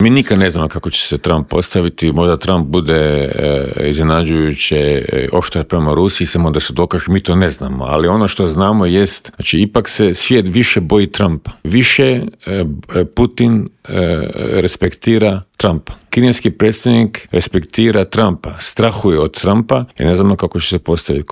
ZAGREB - Uoči druge godišnjice početka ruske agresije na Ukrajinu, u Intervjuu Media servisa razgovarali smo s bivšim ministrom vanjskih poslova Mirom Kovačem, koji nam je kratko proanalizirao trenutno stanje u Ukrajini, odgovorio na pitanje nazire li se kraj ratu, a osvrnuo se i na izbor novog glavnog tajnika NATO saveza i na nadolazeći sastanak Europskog vijeća.